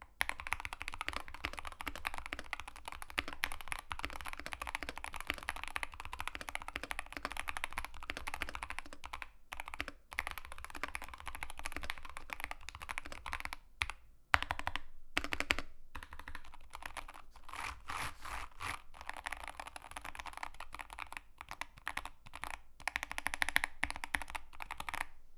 keychronk2he-soundtest.wav